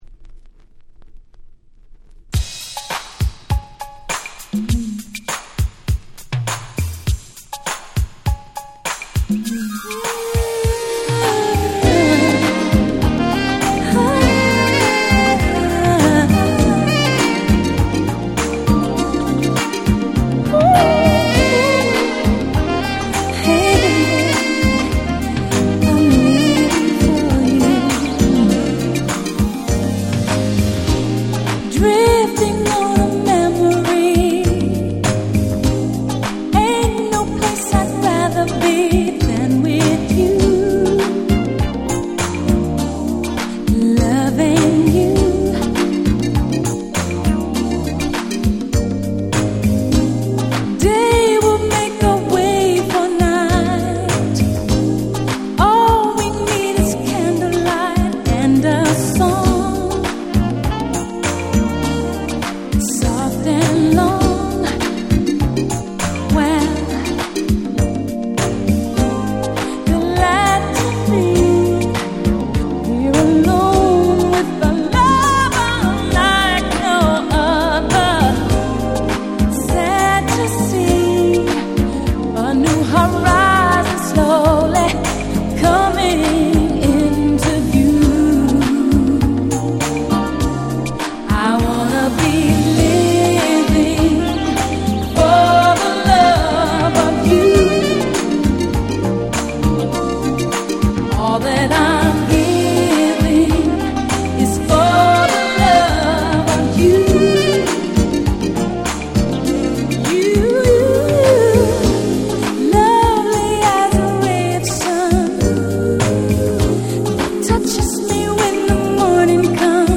87' Super Hit R&B / Slow Jam !!